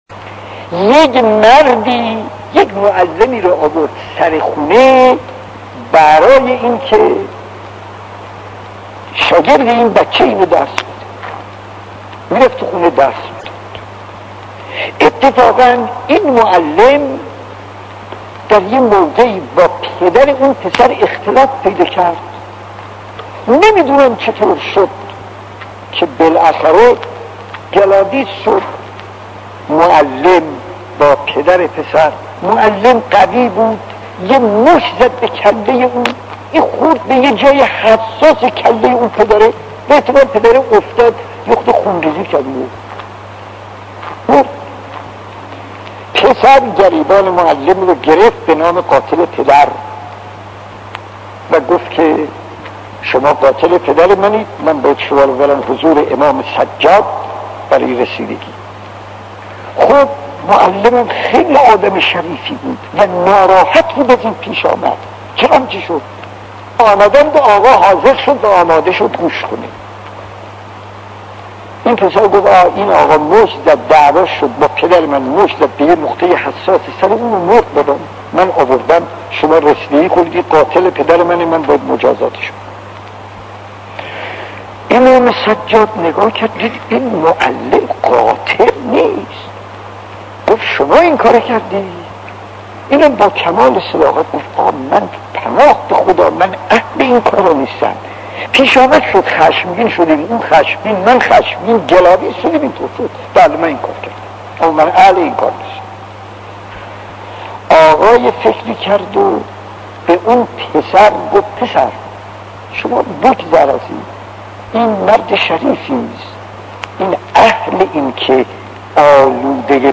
داستان 21 : امام سجاد و معلم قاتل خطیب: استاد فلسفی مدت زمان: 00:06:39